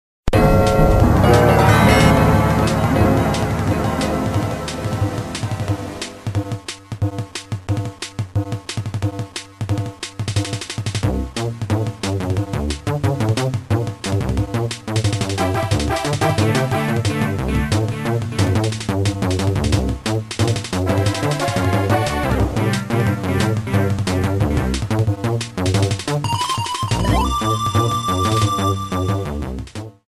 Boss battle music